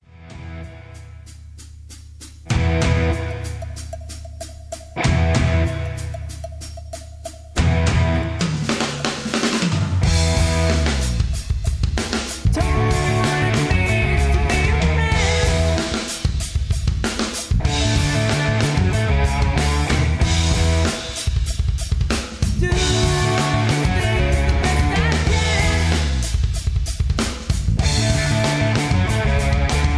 Tags: backing tracks, karaoke, sound tracks, rock